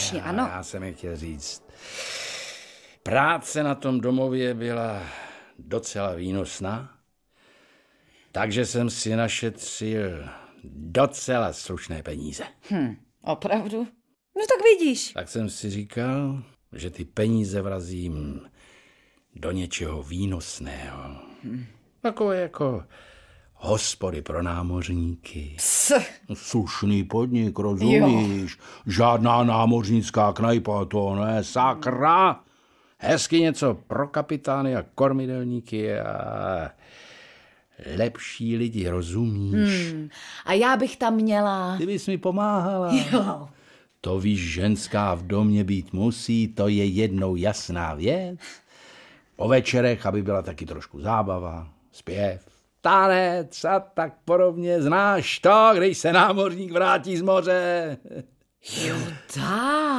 Audiobook
Read: Jan Dolanský